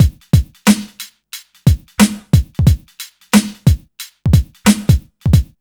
HM90BEAT2 -R.wav